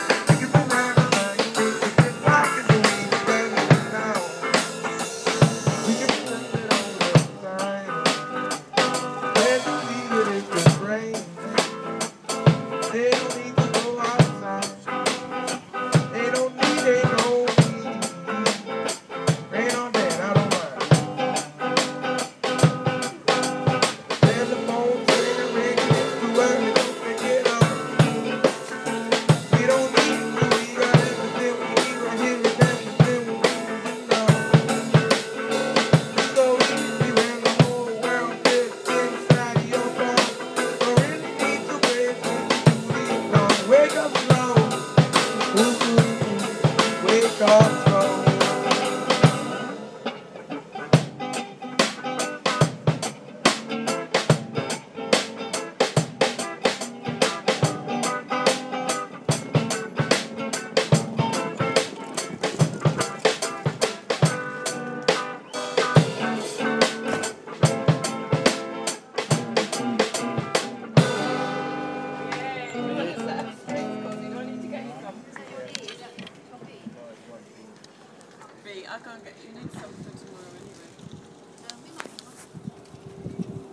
Totally superb, North cross road, london SE22, pop up front garden busk.